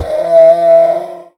Minecraft Version Minecraft Version snapshot Latest Release | Latest Snapshot snapshot / assets / minecraft / sounds / mob / horse / donkey / death.ogg Compare With Compare With Latest Release | Latest Snapshot